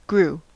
Uttal
Uttal US Okänd accent: IPA : /ɡɹuː/ Ordet hittades på dessa språk: engelska Ingen översättning hittades i den valda målspråket.